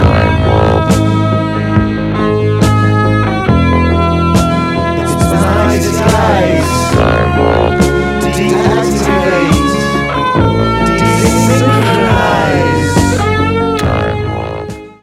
vocals / guitars
rhythm guitar
bass
drums
percussion
backing vocals